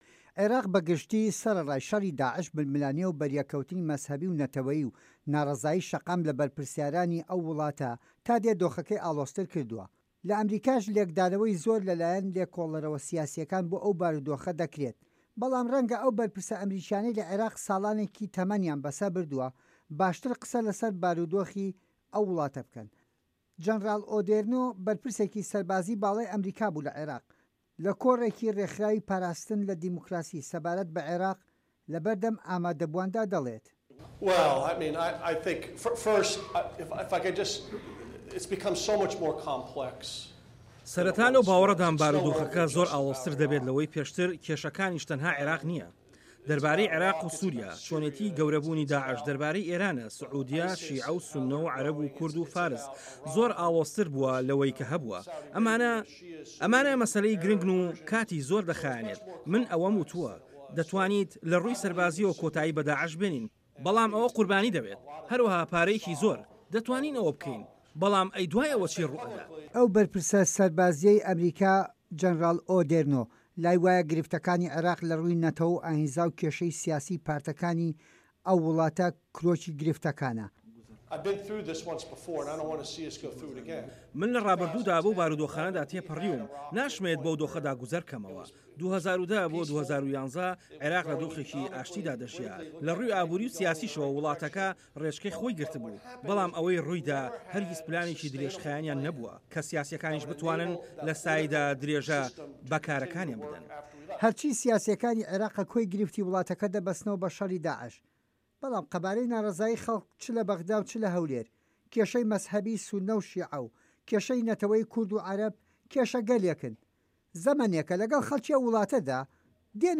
Outgoing Army Chief of Staff Gen. Ray Odierno speaks during his final news briefing at the Pentagon, Aug. 12, 2015.
دەقی ڕاپـۆرتەکە